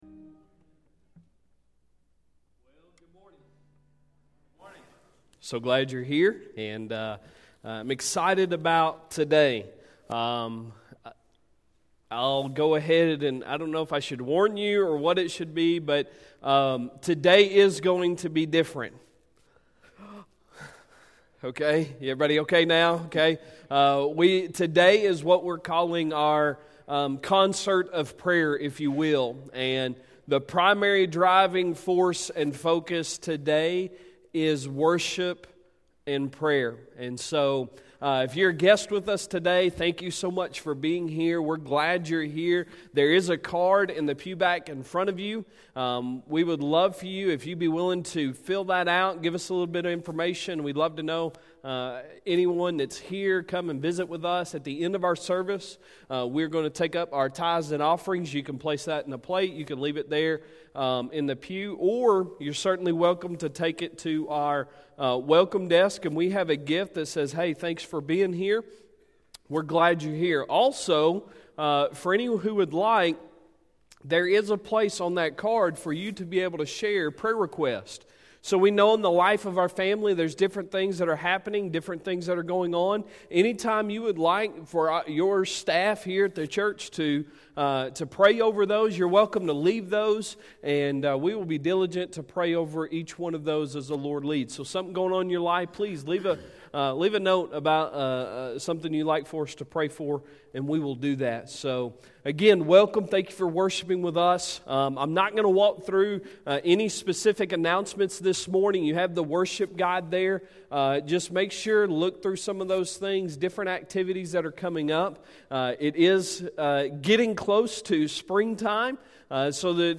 Sunday Sermon March 1, 2020